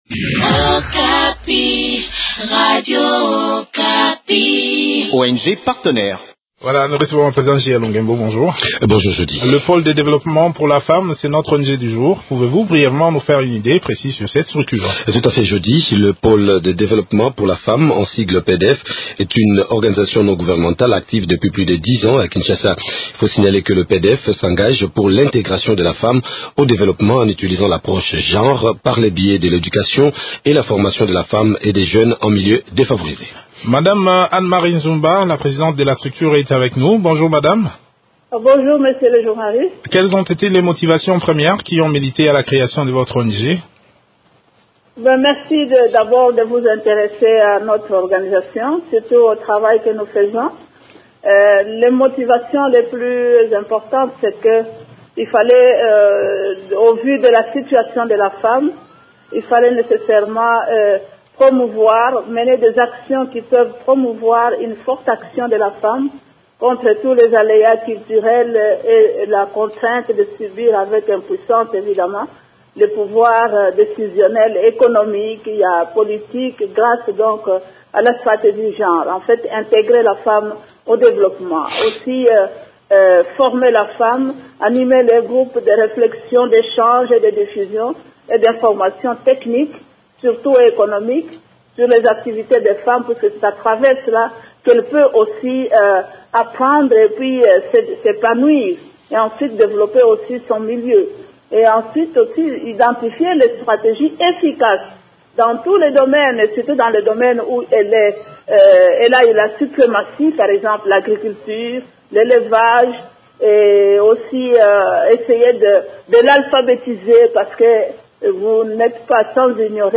s’entretien avec